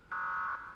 Monster_07_Attack.wav